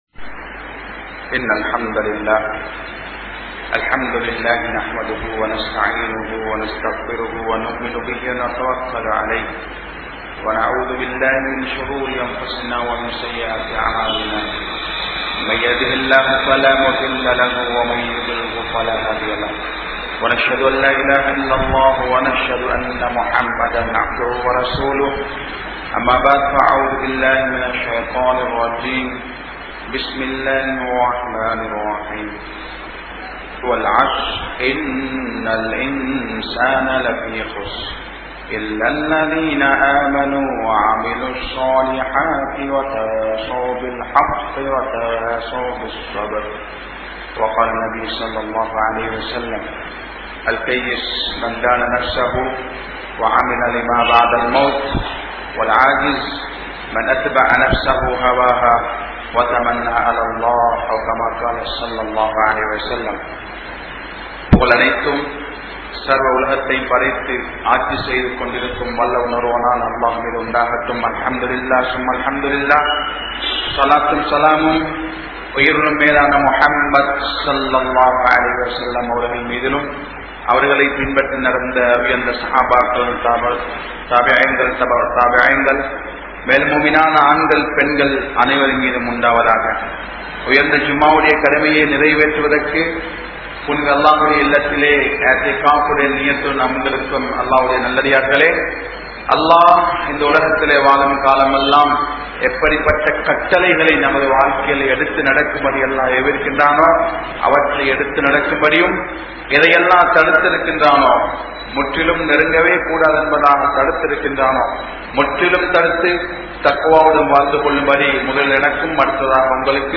Naam Seium Paavaththin Vilaivu (நாம் செய்யும் பாவத்தின் விளைவு) | Audio Bayans | All Ceylon Muslim Youth Community | Addalaichenai